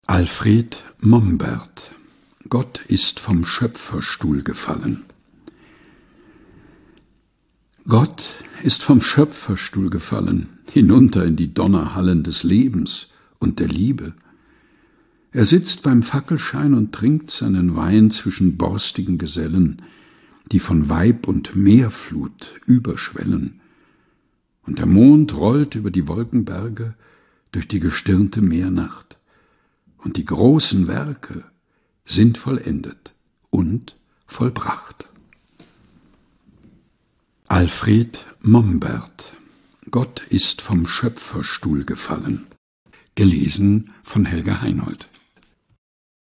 Texte zum Mutmachen und Nachdenken - vorgelesen
liest sie in seinem eigens zwischen Bücherregalen eingerichteten, improvisierten Studio ein.